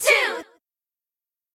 twogirls.ogg